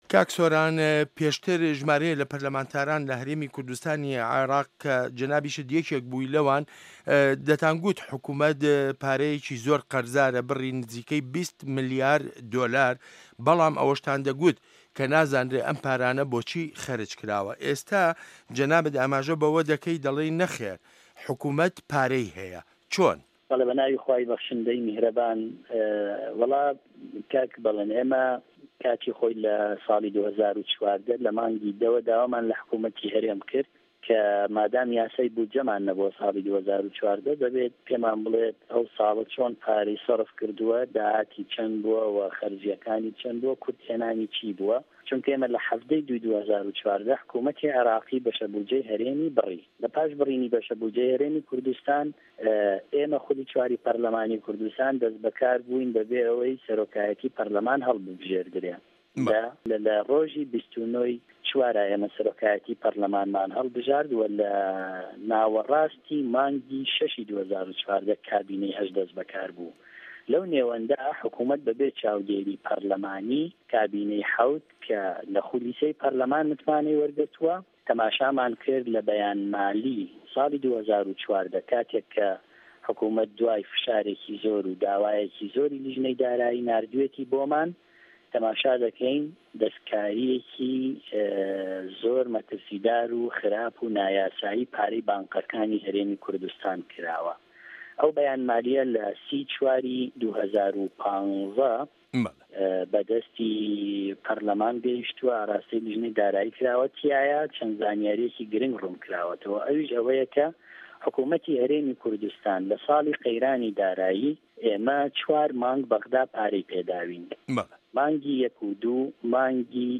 وتووێژ لەگەڵ سۆران عومەر